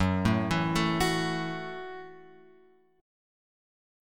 F# Minor 7th